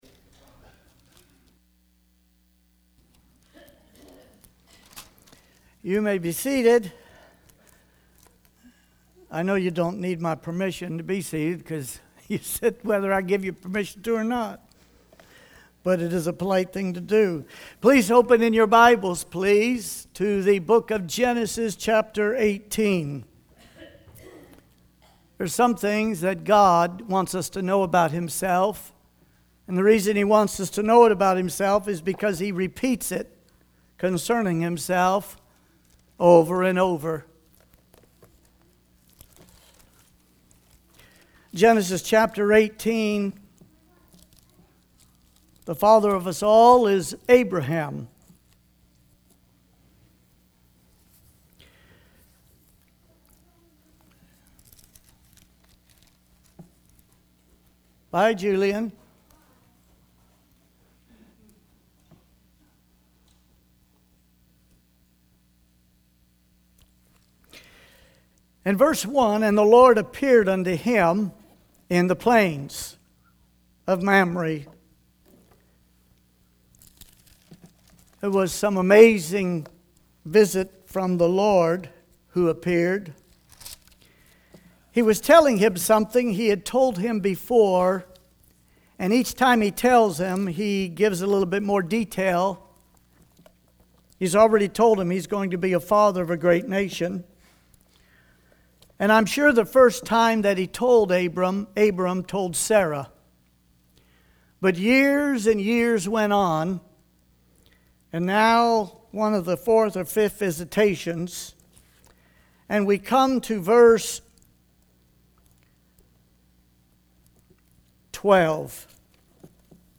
Morning Sermons